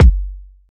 Kick (35).wav